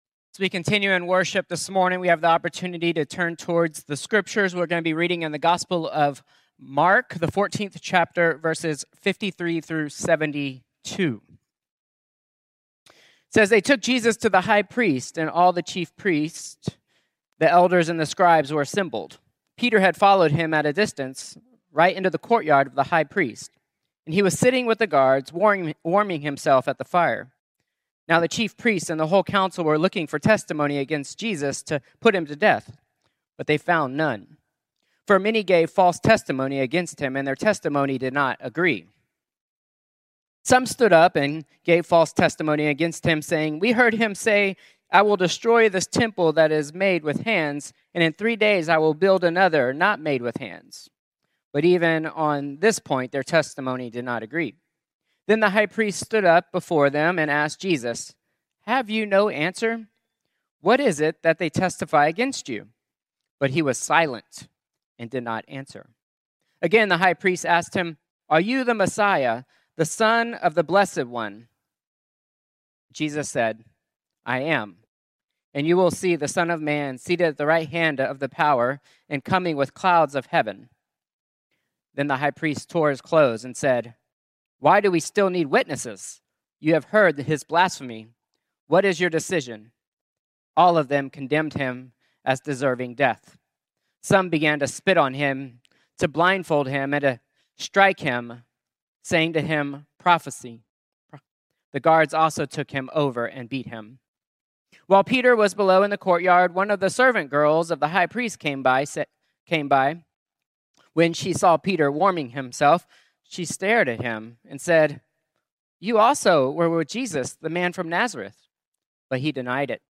Contemporary Worship 3-1-2026